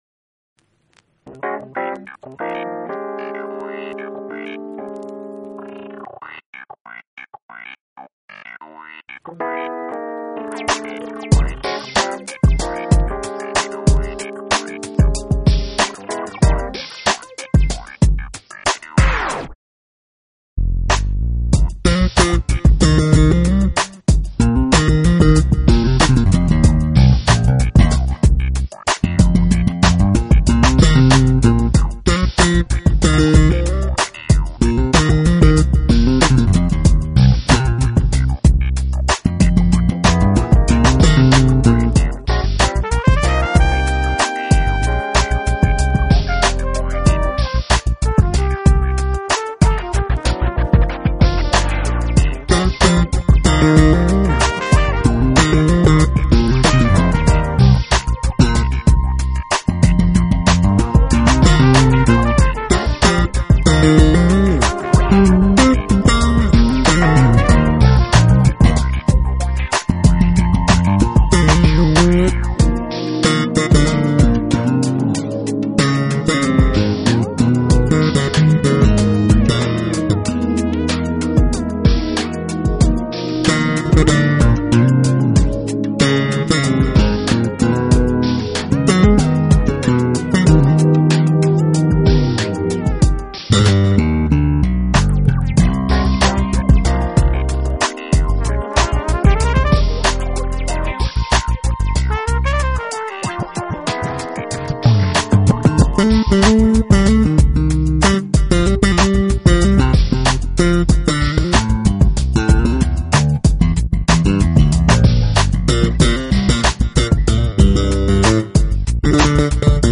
discover a provocatively funky new voice in today’s jazz.
flugelhorn
jazz textures calling you the listener in for a short visit.